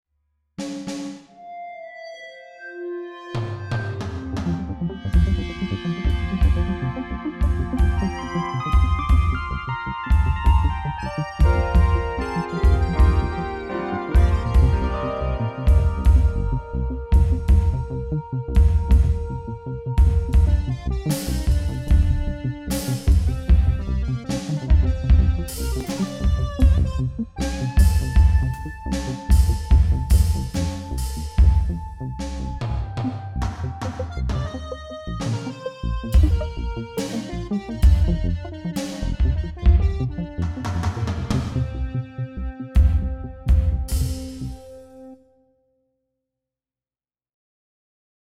Made with Free and Open Source Software analog synthesizer simulation, and LADSPA plugins on Linux (no windows was used at all for this music) this piece of music (not crap) which is just testing some possibilities features analog synth sounds with effects with actual synth sounds, not some prefab samples.